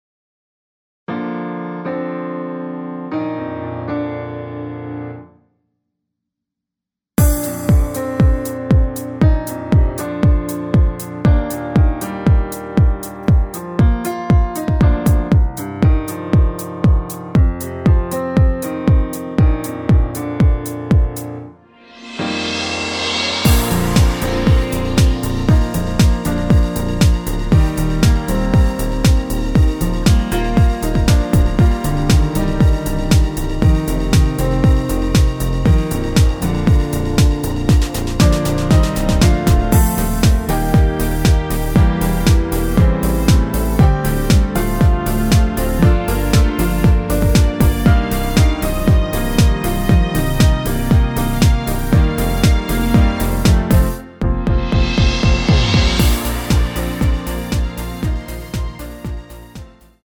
전주가 없는 곡이라 2마디 전주 만들어 놓았습니다.(미리듣기 참조)
(-4) 내린 MR 입니다
Bb
앞부분30초, 뒷부분30초씩 편집해서 올려 드리고 있습니다.